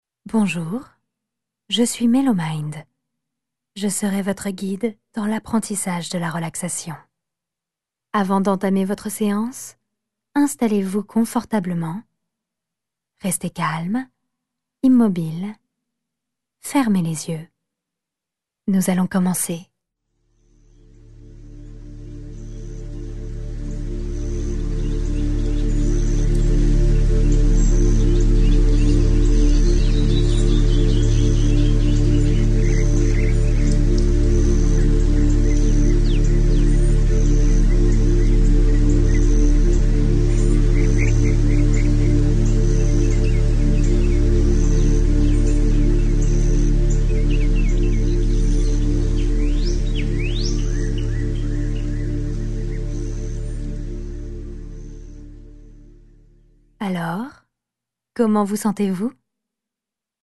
Laissez-vous transporter par la voix de Melomind.
Installez-vous confortablement, mettez vos écouteurs, vous allez être immergés dans un environnement sonore relaxant.